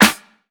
Game_Snare_3.wav